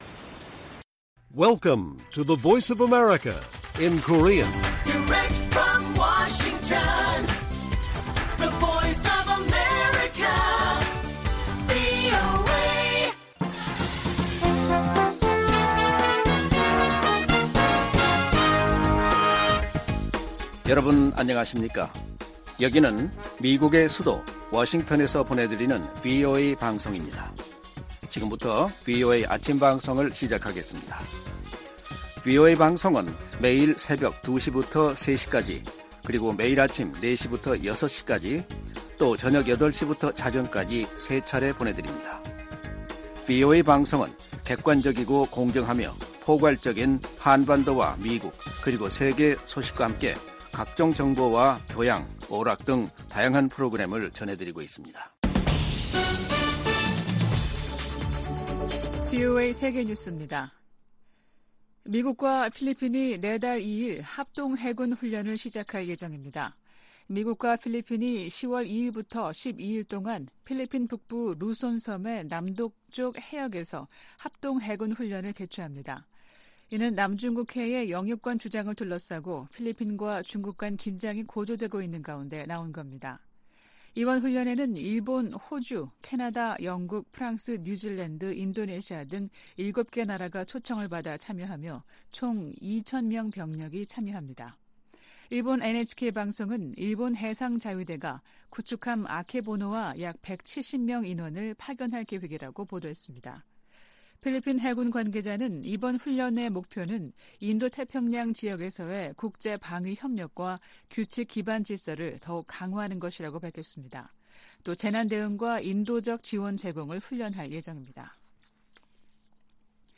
세계 뉴스와 함께 미국의 모든 것을 소개하는 '생방송 여기는 워싱턴입니다', 2023년 9월 29일 아침 방송입니다. '지구촌 오늘'에서는 로이드 오스틴 미 국방장관이 취임 후 첫 아프리카 3개국 순방을 마무리한 소식 전해드리고, '아메리카 나우'에서는 공화당 대통령 후보 경선 2차 토론회에 도널드 트럼프 전 대통령을 제외한 7명이 참가해 국경 정책과 우크라이나 지원 등에 관해 공방한 이야기 살펴보겠습니다.